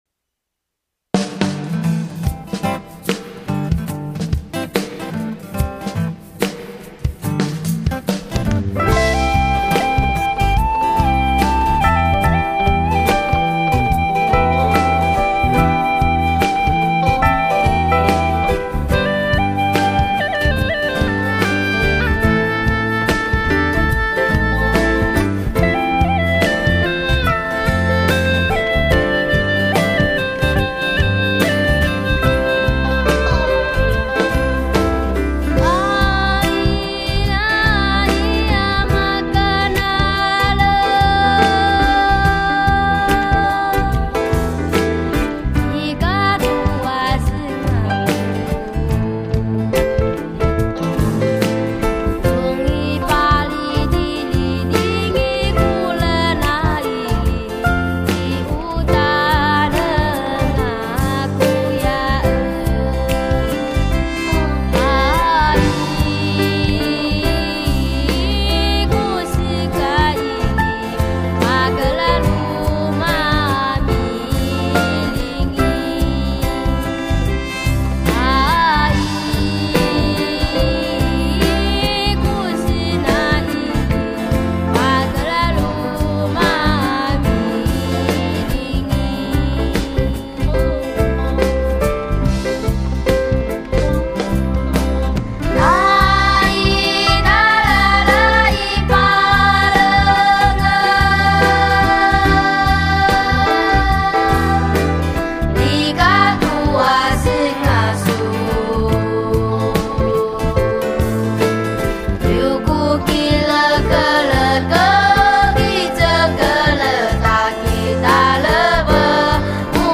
录音室：高雄亚洲数位录音室
这不仅仅是张音乐专辑，每首歌谣更好像说故事一般，用孩子的歌声，天真、原始、自然的述说著孩子在传统歌谣成长的每段记忆。
优美的旋律如涟漪穿透每一个角落，与北大武山的每次深情对唱此起彼落，自然形成的卡农旋律，是山林与孩子最真的呼唤...